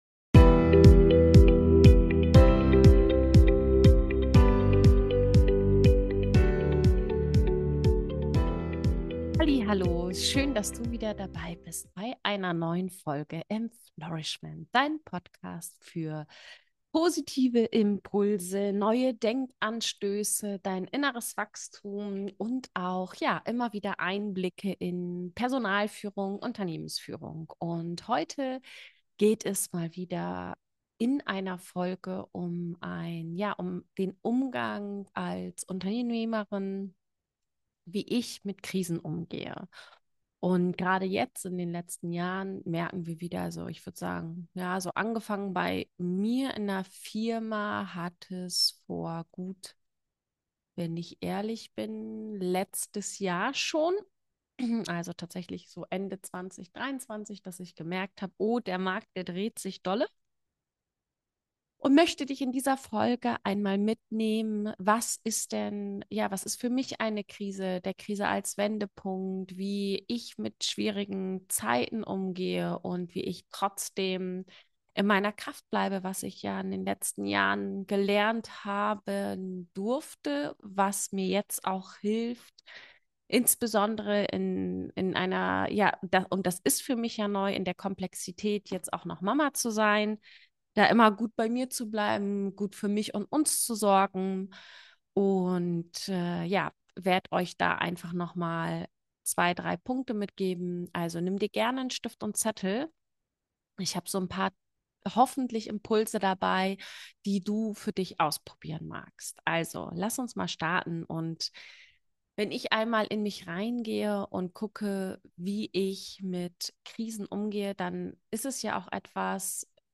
Beschreibung vor 11 Monaten In dieser Solo-Episode nehme ich dich mit in ein Thema, das uns alle betrifft – aber oft sprachlos macht: den Umgang mit Krisen.